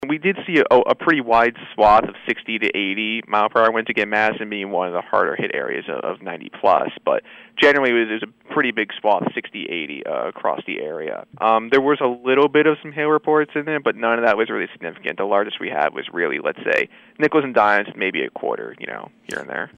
He says several areas were hit by strong winds.